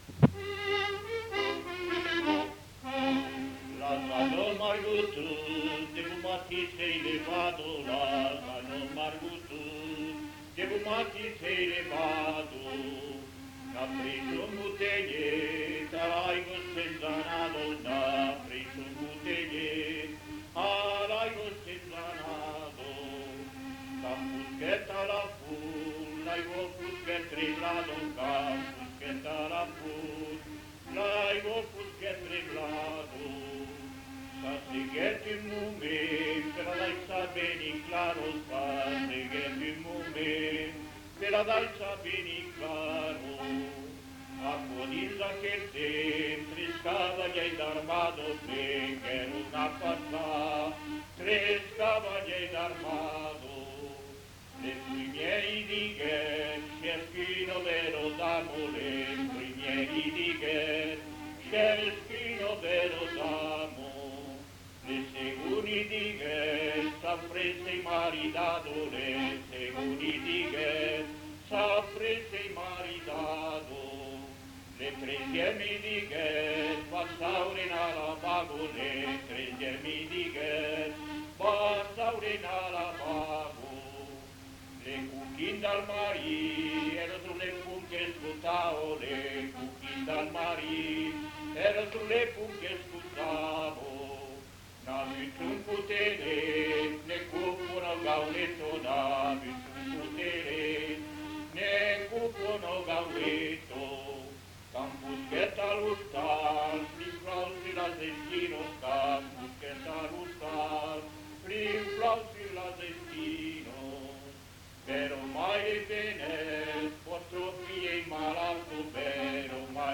Lieu : Villardonnel
Genre : chanson-musique
Type de voix : voix d'homme
Production du son : chanté
Instrument de musique : accordéon diatonique
Classification : mariage (diverses)